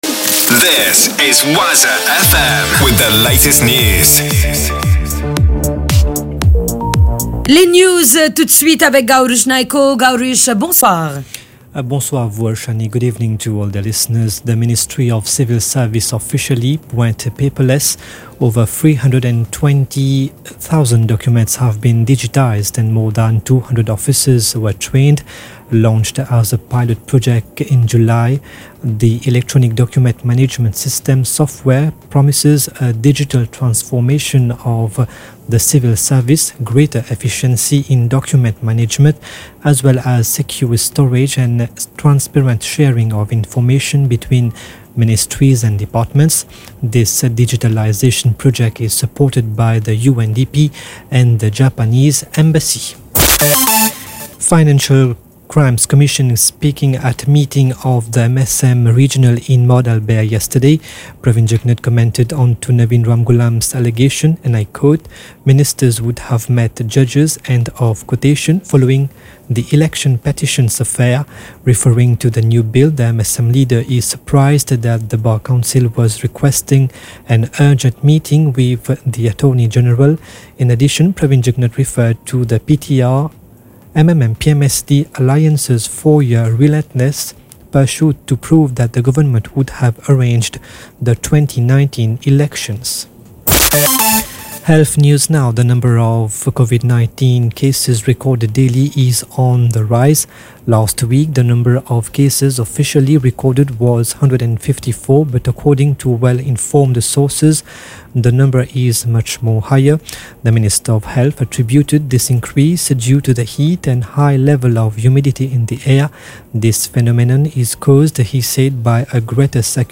NEWS 18H - 15.12.23